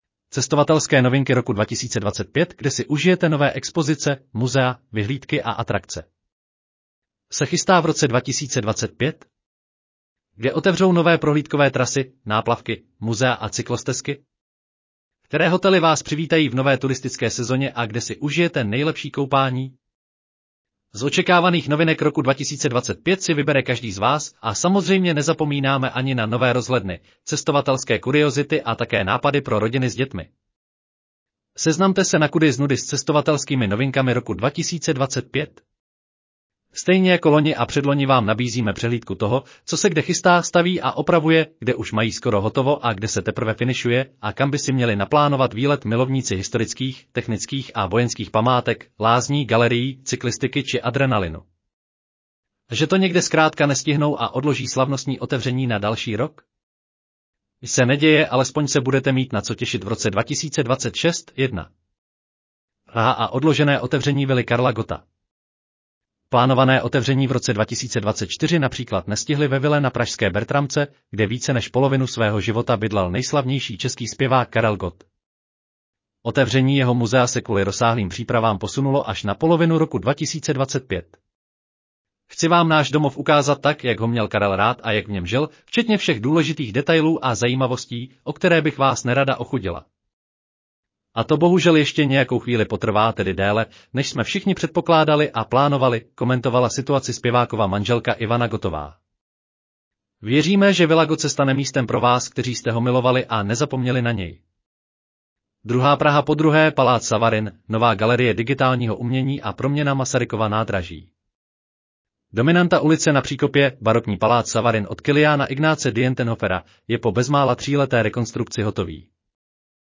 Audio verze článku Cestovatelské novinky roku 2025: kde si užijete nové expozice, muzea, vyhlídky a atrakce